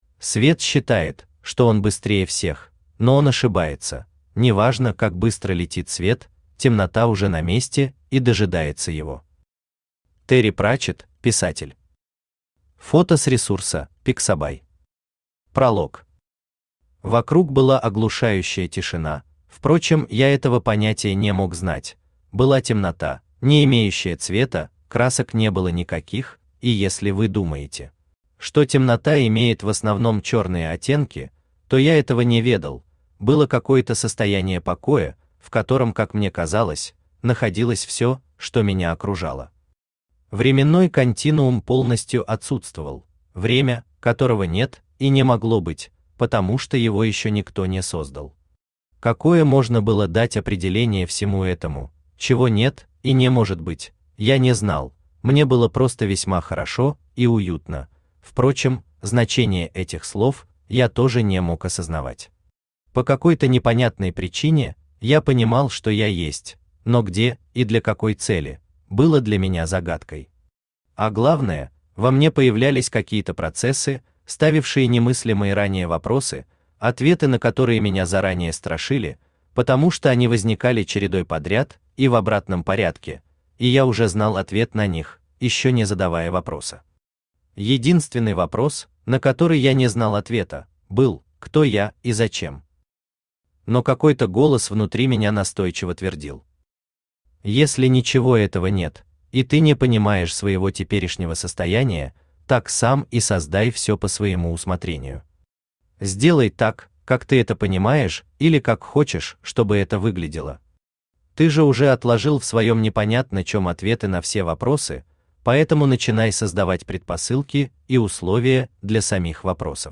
Aудиокнига Записки интроверта Автор Валерий Муссаев Читает аудиокнигу Авточтец ЛитРес.